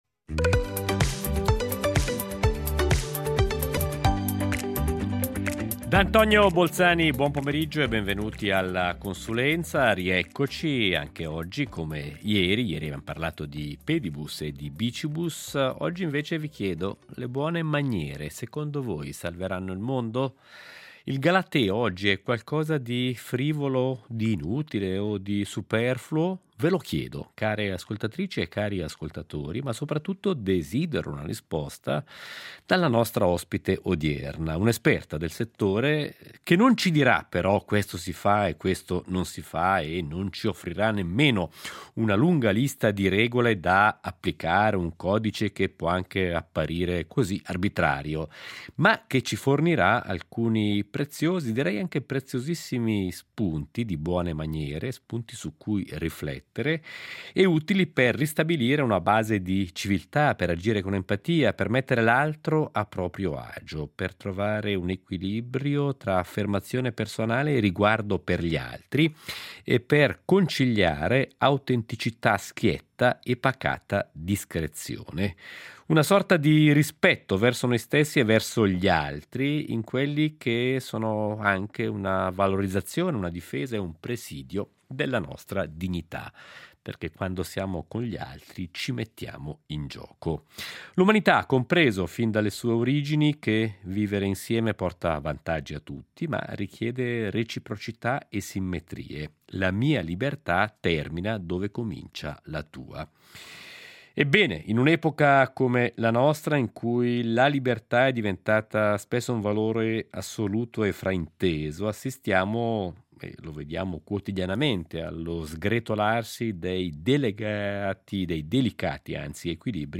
La consulenza Il galateo delle buone maniere: la finezza nei modi e l’eleganza nel pensiero salveranno il mondo?